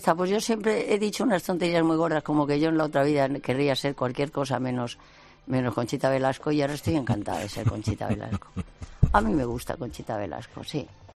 Concha Velasco en su última entrevista con Carlos Herrera